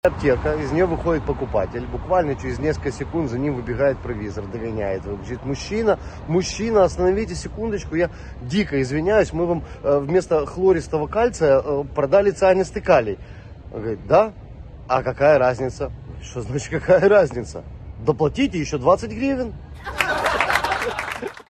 Анекдот mp3